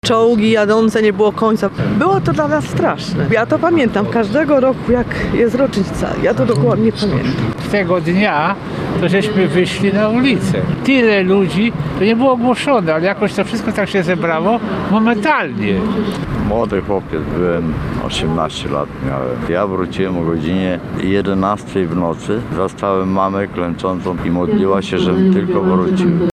Jak mieszkańcy Trójmiasta wspominają te dni?